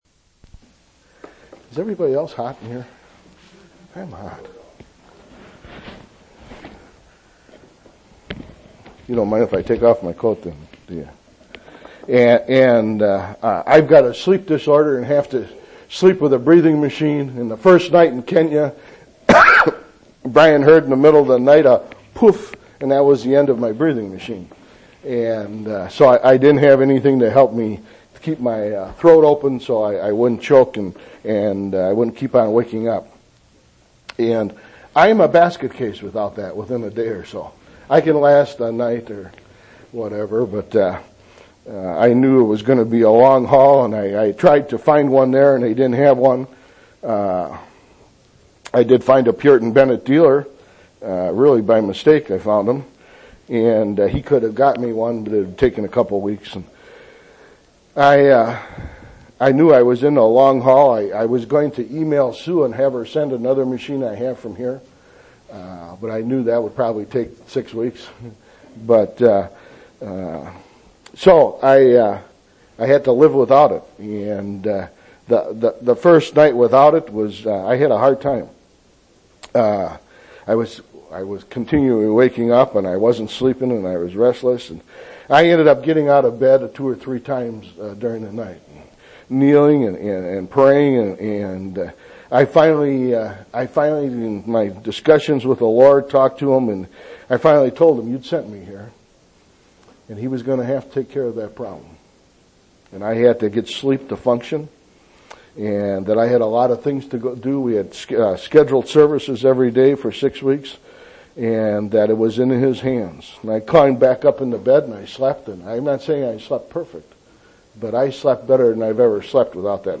10/22/2000 Location: East Independence Local Event